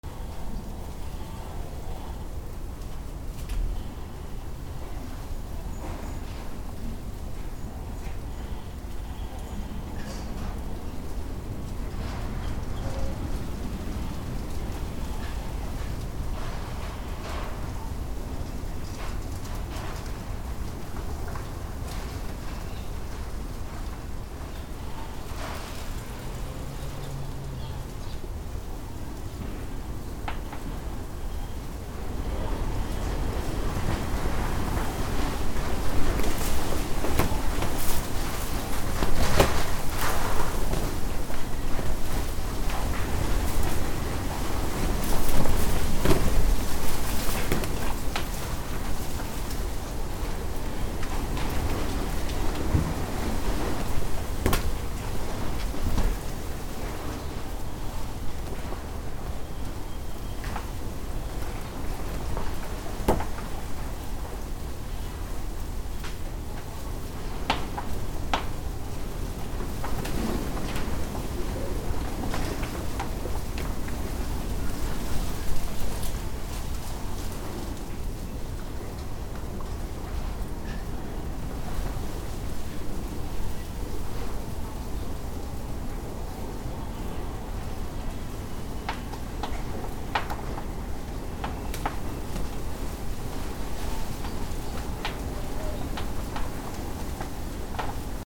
/ A｜環境音(天候) / A-45 ｜台風 嵐
嵐 雨 強風
ウウー